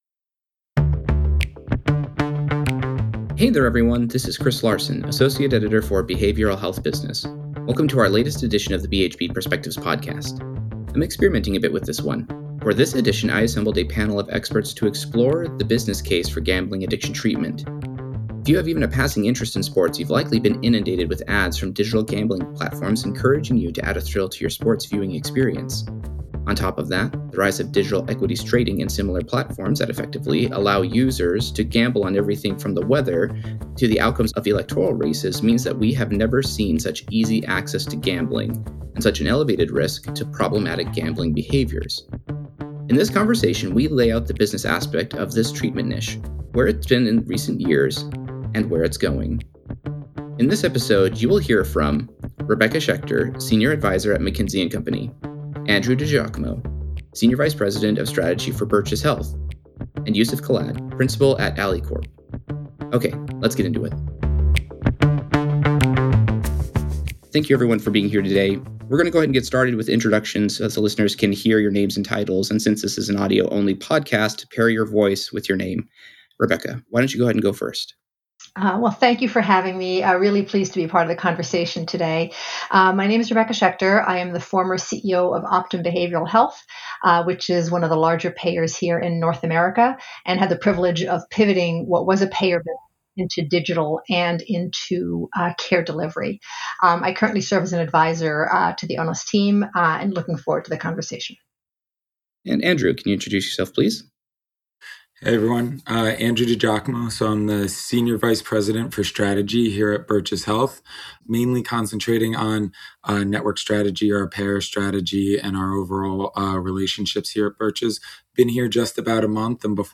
On today's BHB Perspectives podcast, we are joined by three industry insiders to explore the business case of gambling addiction treatment. In an age where anyone can now gamble on just about anything, the expert panel assembled by BHB explores why swifter action by the industry is called for, what it takes to meet a new generation of problem gambling and how such a treatment fits into the ever-evolving behavioral health industry.